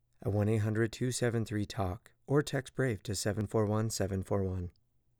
Random pops and clicks in recording and playback
I am using a scarlett solo interface and a Rode NT1 mic.
It looks like a “drop-out” (a small part of the waveform is missing).